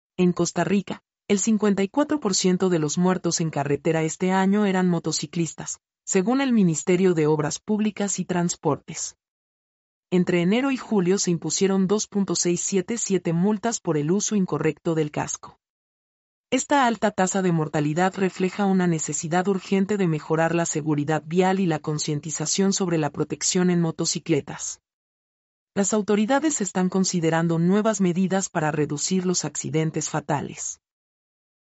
mp3-output-ttsfreedotcom-73-1.mp3